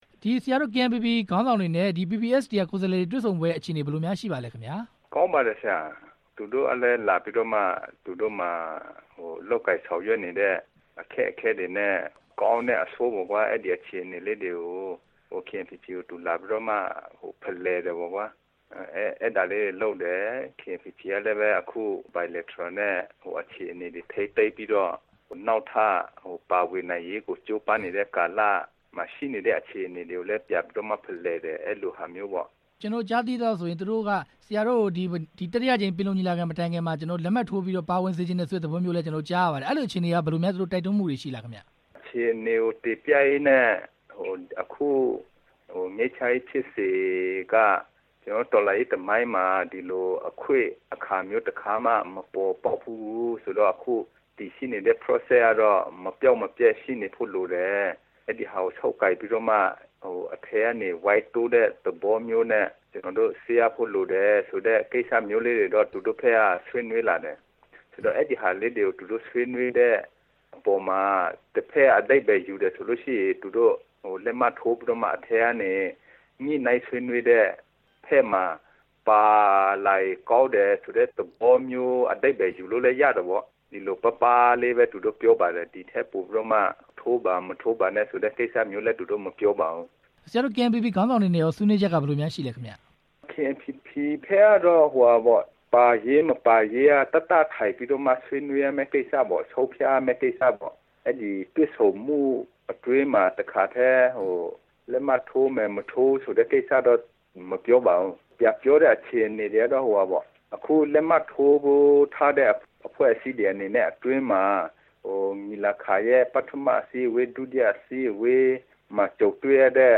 knpp ခေါင်းဆောင်တွေနဲ့ ppst ကိုယ်စားလှယ်တွေ တွေ့ဆုံပွဲအကြောင်း မေးမြန်းချက်